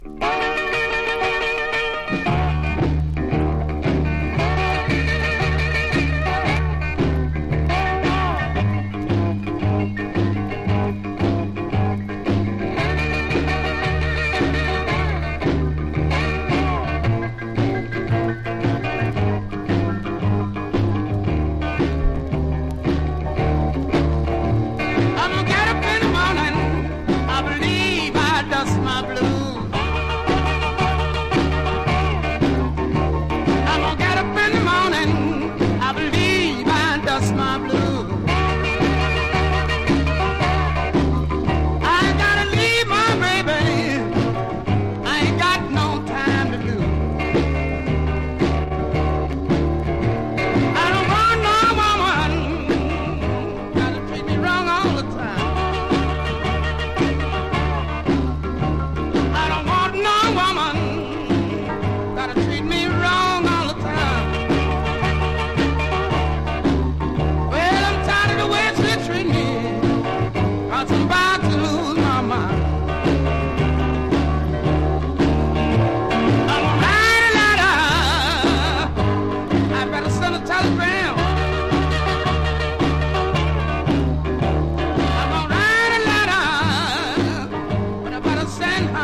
UKオリジナル・モノラル盤。
RHYTHM & BLUES